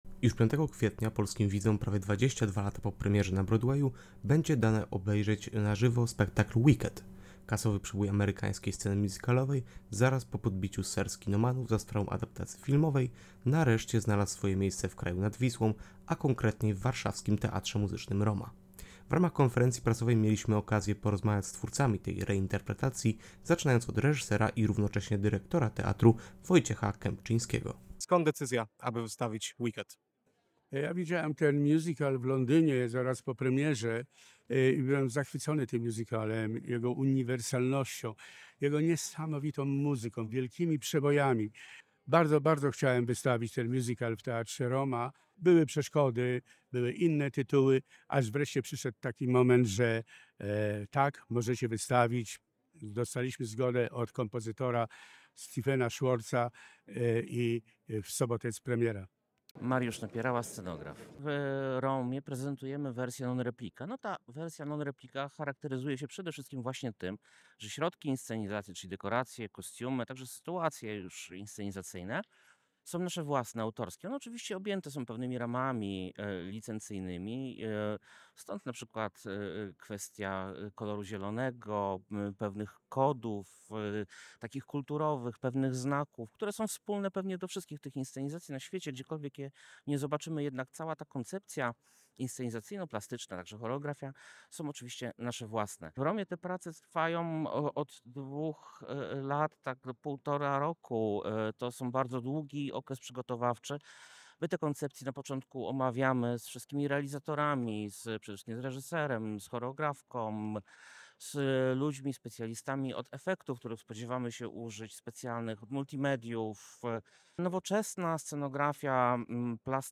Podczas konferencji prasowej twórcy zdradzili kulisy pracy nad „Wicked”.